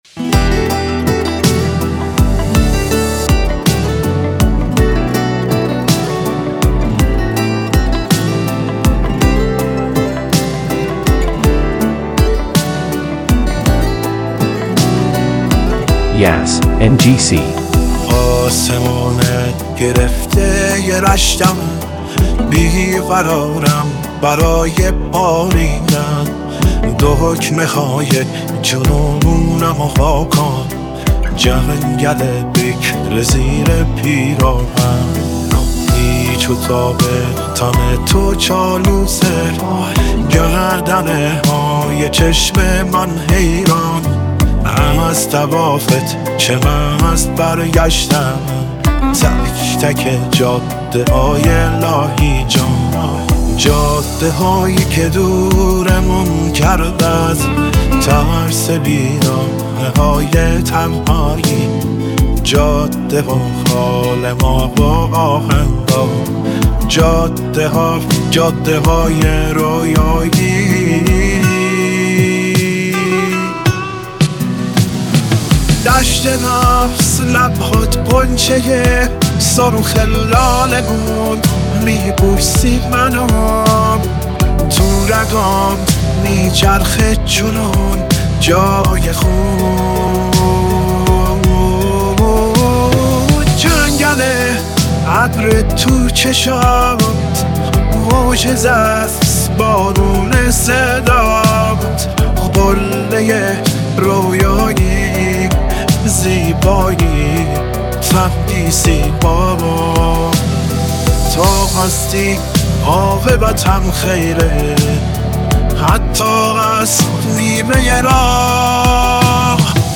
ژانر: پاپ / رپ